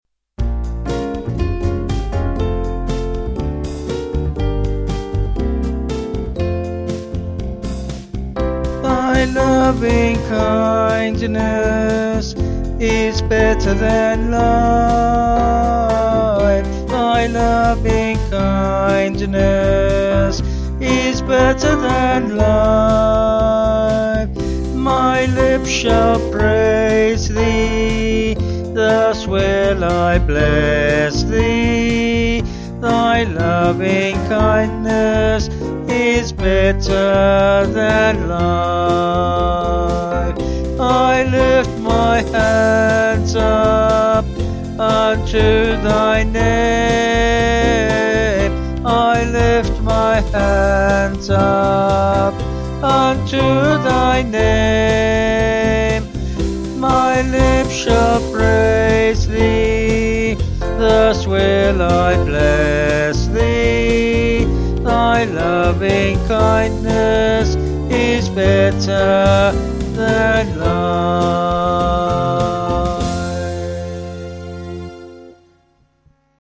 Vocals and Band   182.6kb